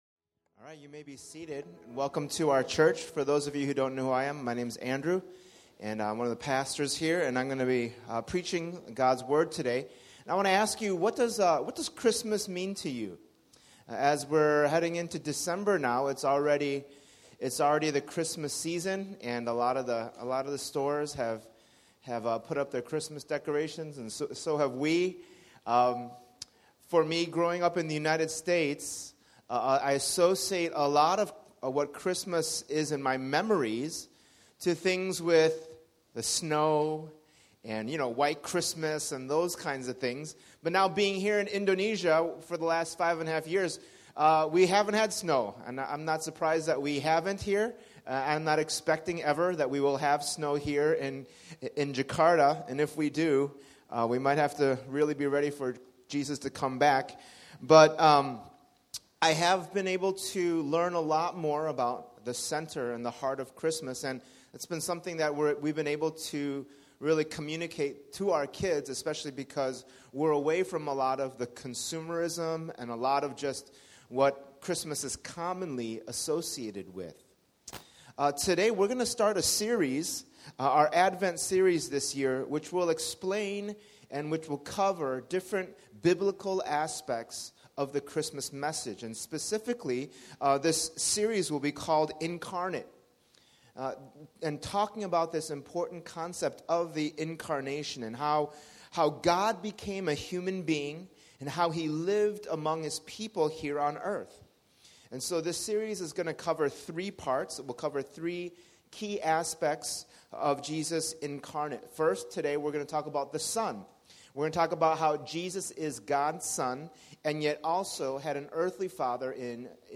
We invite you to our Advent sermon series as we study how Jesus is truly God “Incarnate,” or God in human form.